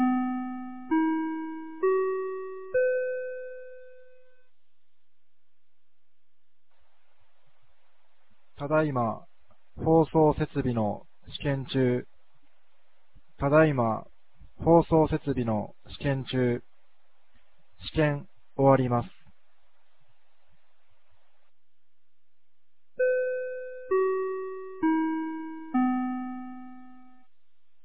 2025年08月16日 16時03分に、由良町から全地区へ放送がありました。